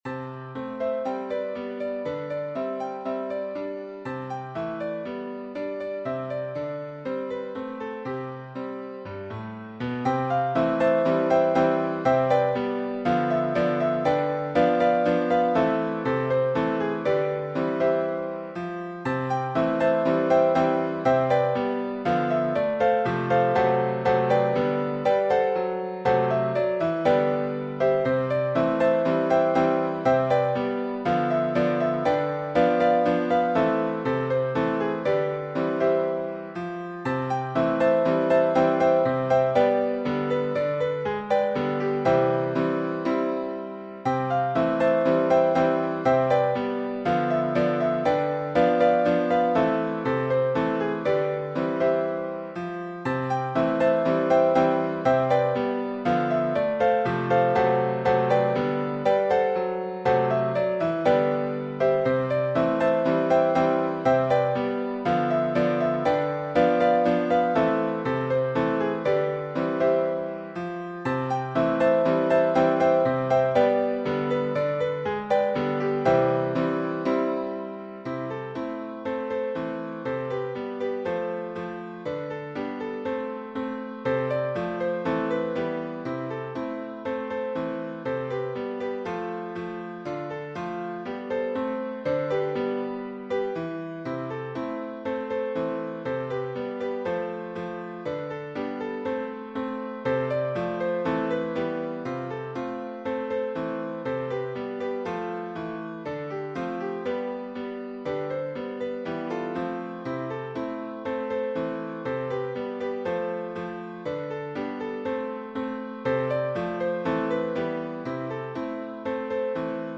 Melancholic
Classic ragtime
Piano only